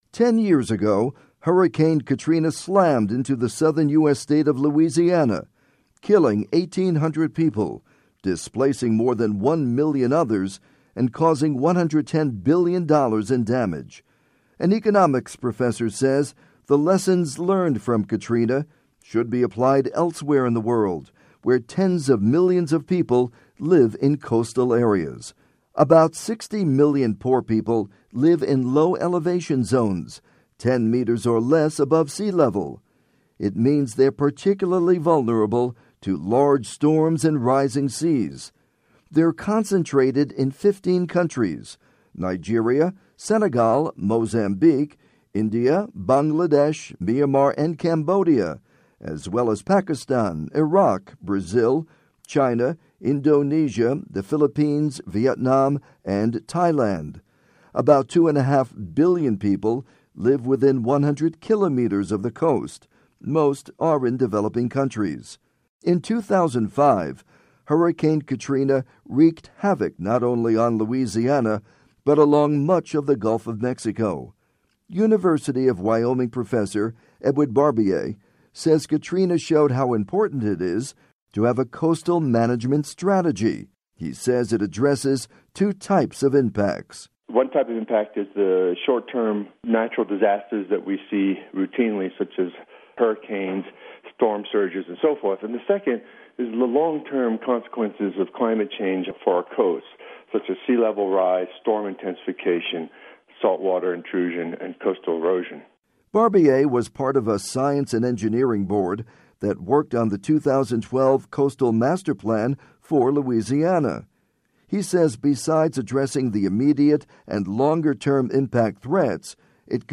report on lessons learned from Hurricane Katrina